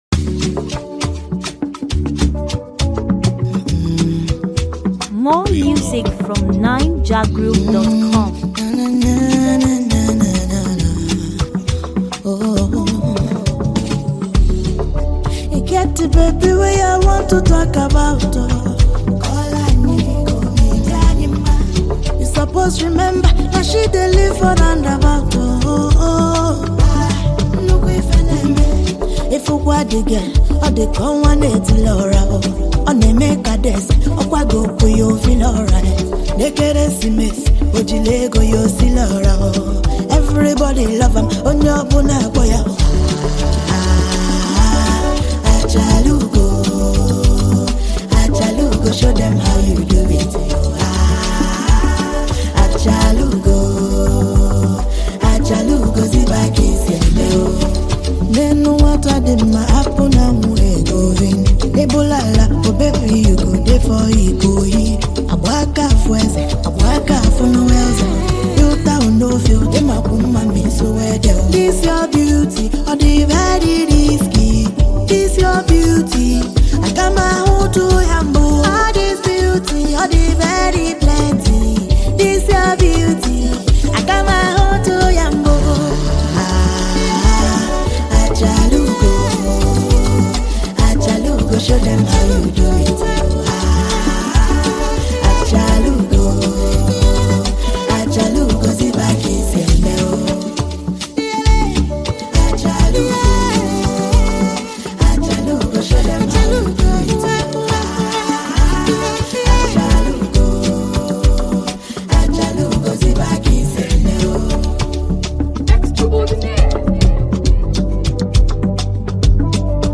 Latest, Naija-music
a phenomenally talented Nigerian female fast-emerging artist
urban-grooving lyrical volume